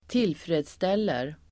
Uttal: [²t'il:fre:dstel:er]